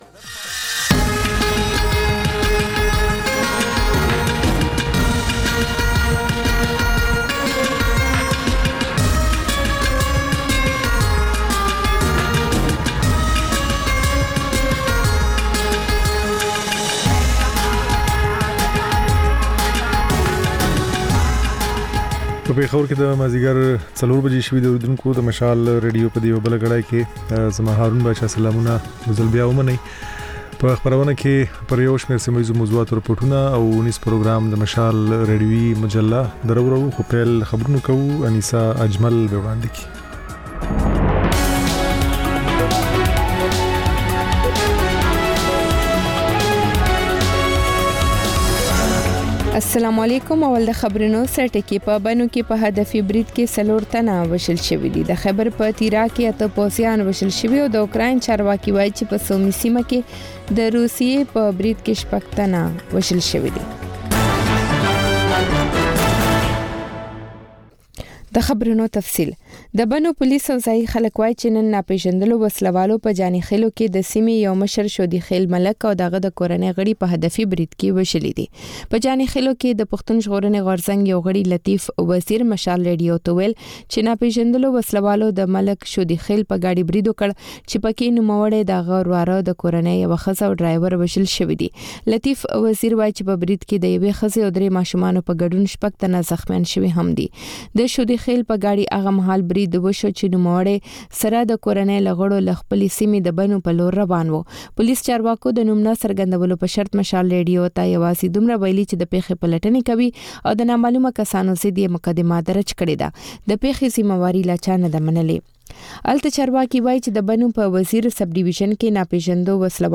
د مشال راډیو مازیګرنۍ خپرونه. د خپرونې پیل له خبرونو کېږي، ورسره اوونیزه خپرونه/خپرونې هم خپرېږي.